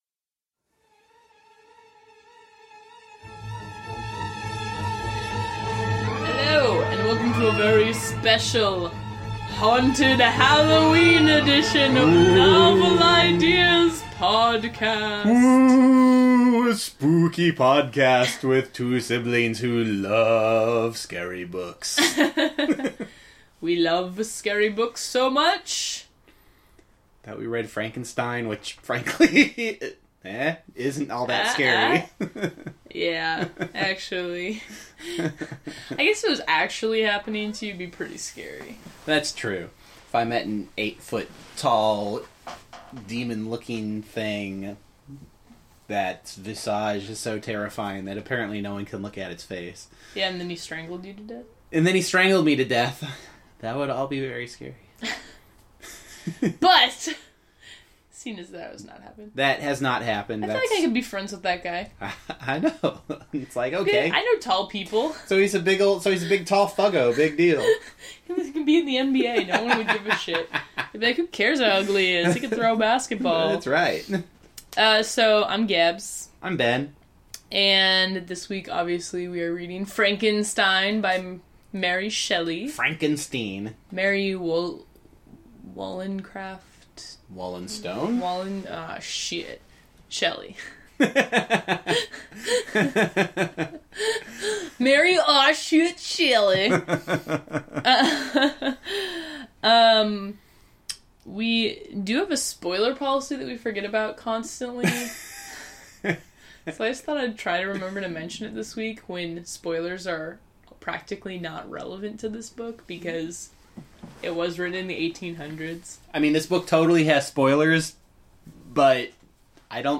The music bump is “Night on Bald Mountain” by Modest Mussorgsky because, dude, Halloween.